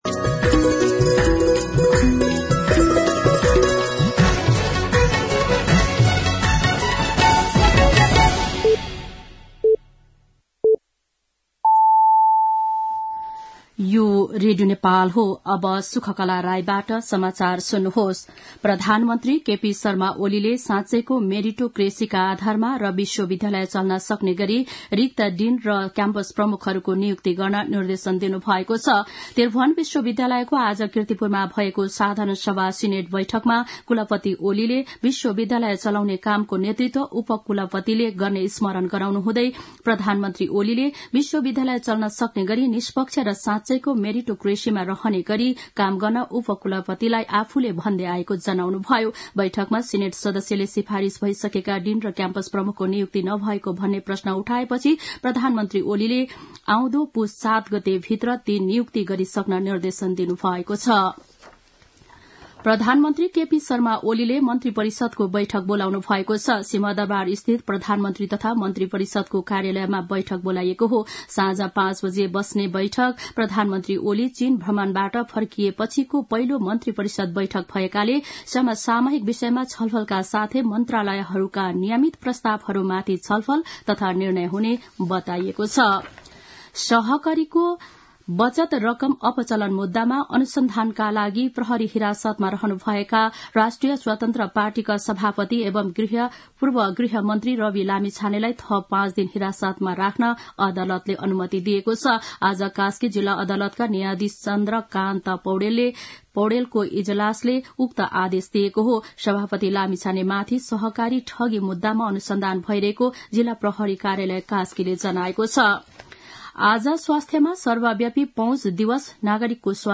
दिउँसो ४ बजेको नेपाली समाचार : २८ मंसिर , २०८१
4-pm-nepali-news.mp3